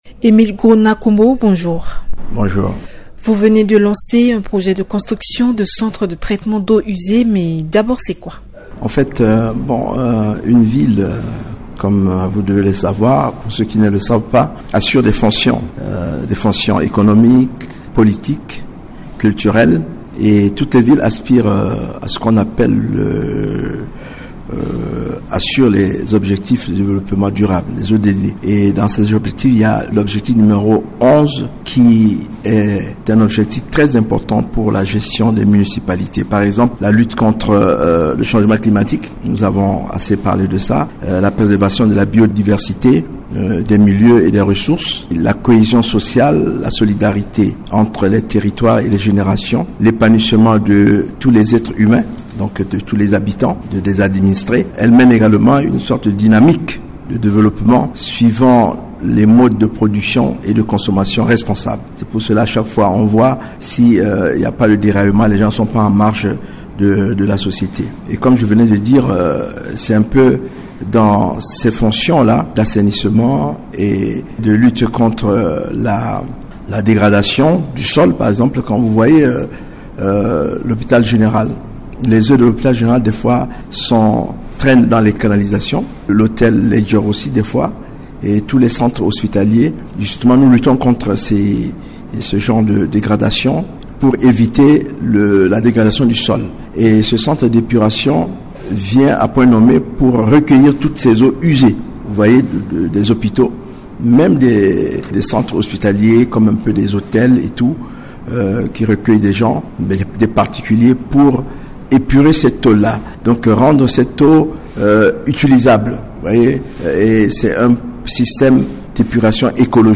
Dans une interview accordée à Radio Ndeke Luka, Emile Gros-Raymond Nakombo, président de la délégation spéciale de Bangui présente l’importance du projet de construction d’un centre de traitement d’eaux usées à l’hôpital général de Bangui. Selon lui, l’objectif vise à recueillir ces eaux usées afin d’éviter d’éventuelles dégradations sur l’environnement.
Invité Maire de Bangui Fr.mp3